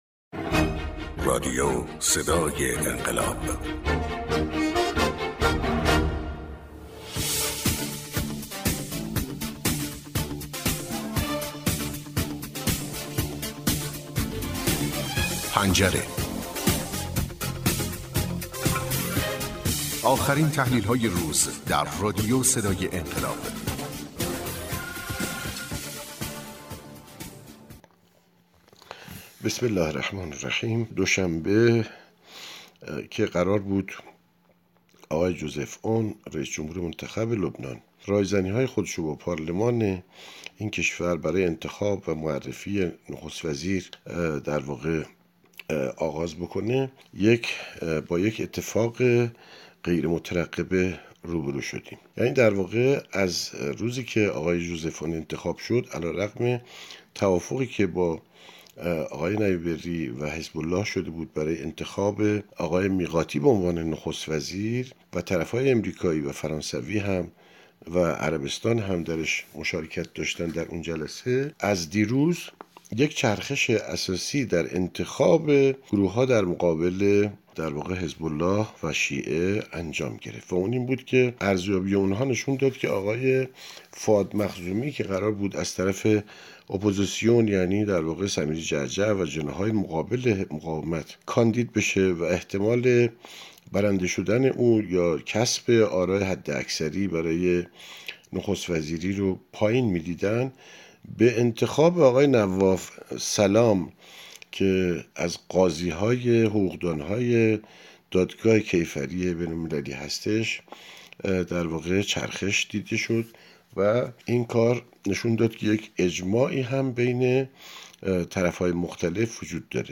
تحلیل گر منطقه و بین الملل